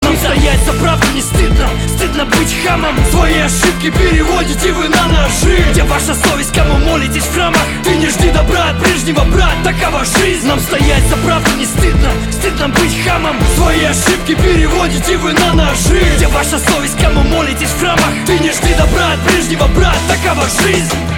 • Качество: 256, Stereo
русский рэп